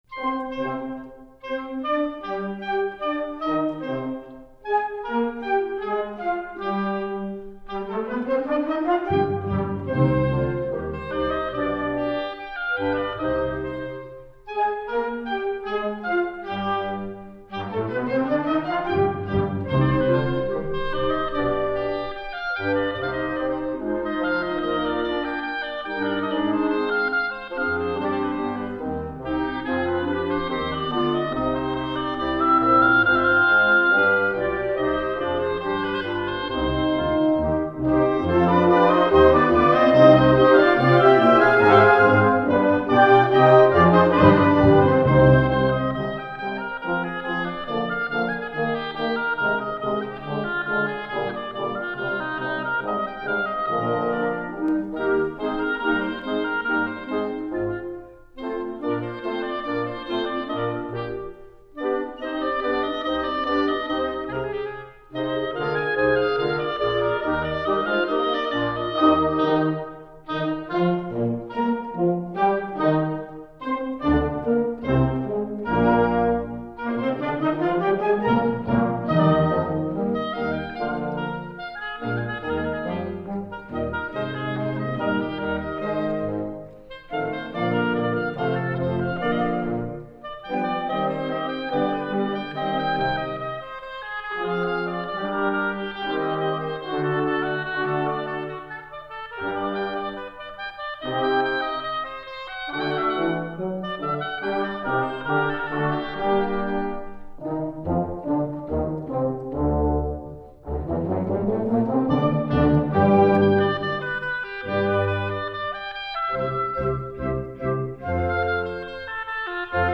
Voicing: Oboe Solo w/ Band